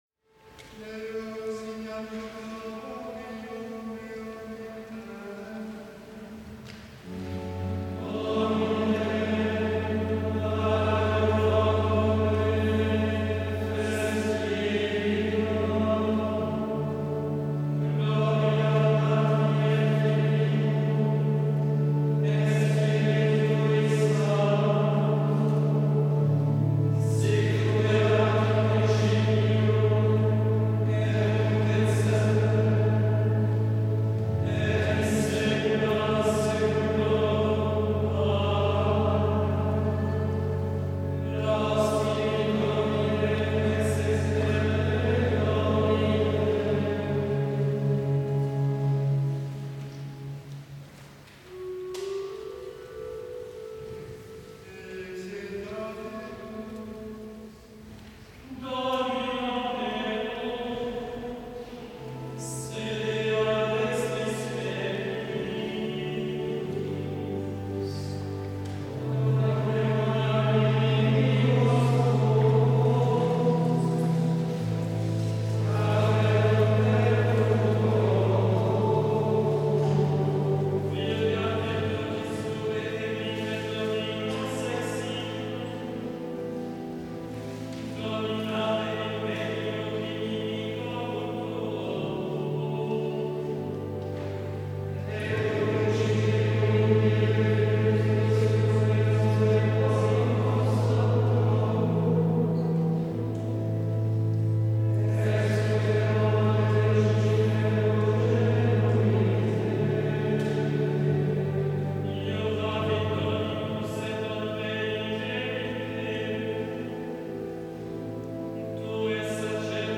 02-Rameaux-vepres.mp3